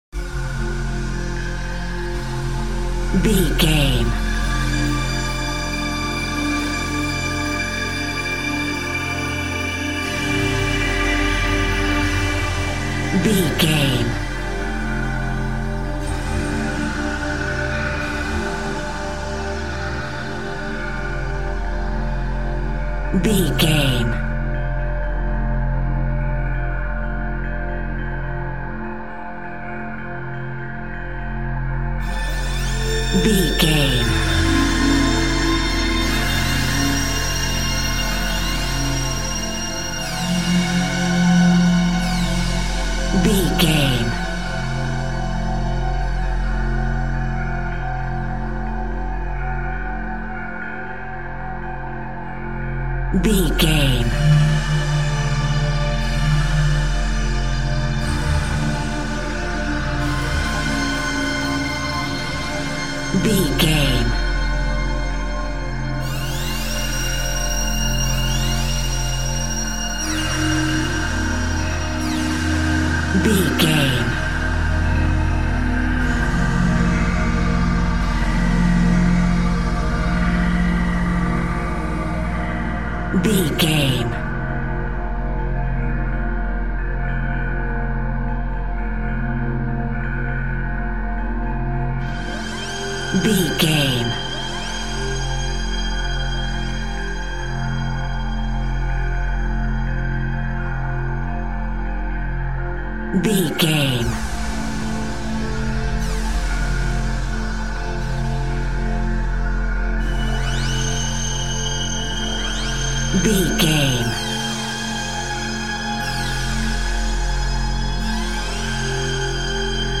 Thriller
Aeolian/Minor
scary
tension
ominous
dark
suspense
eerie
synths
pads
Synth Pads
atmospheres